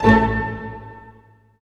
Index of /90_sSampleCDs/Roland LCDP08 Symphony Orchestra/HIT_Dynamic Orch/HIT_Staccato Oct